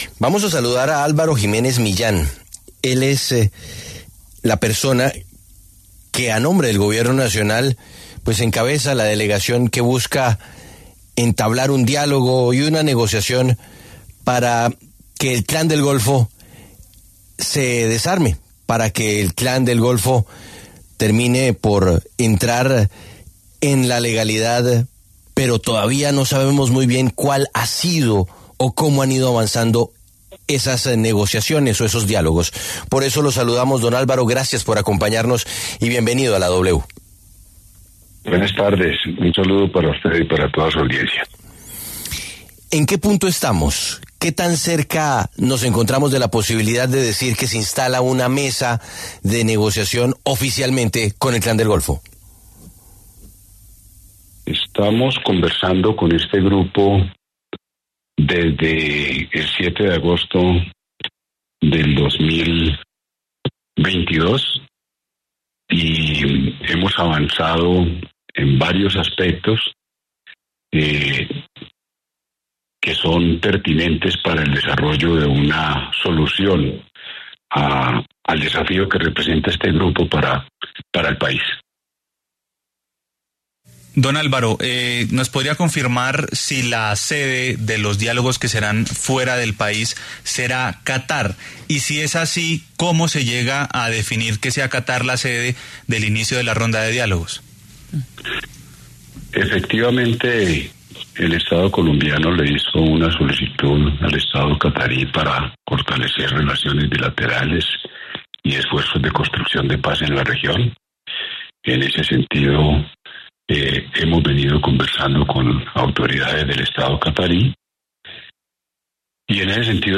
El jefe negociador en entrevista con La W además defendió la búsqueda de paz por parte del gobierno señalando que hay avances en varios aspectos.